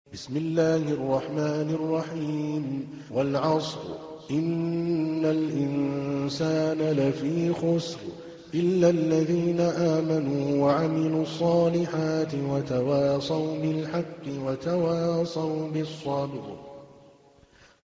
تحميل : 103. سورة العصر / القارئ عادل الكلباني / القرآن الكريم / موقع يا حسين